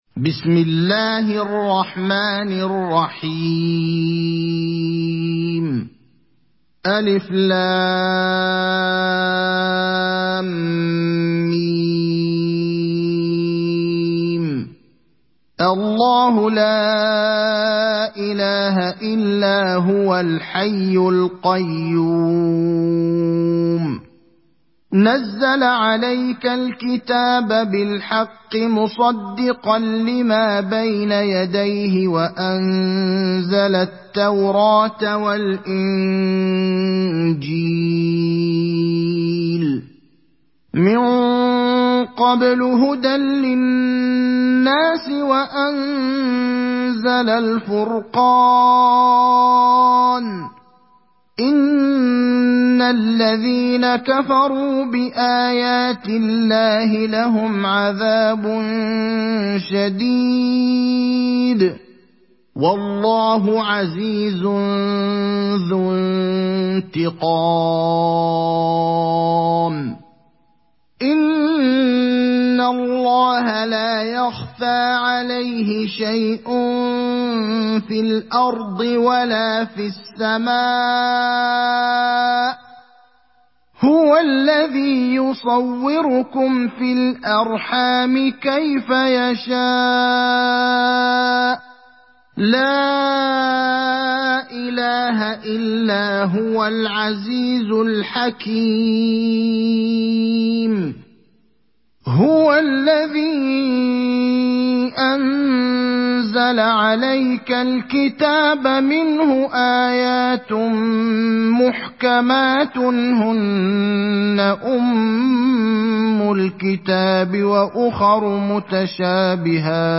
Ali Imran Suresi İndir mp3 Ibrahim Al Akhdar Riwayat Hafs an Asim, Kurani indirin ve mp3 tam doğrudan bağlantılar dinle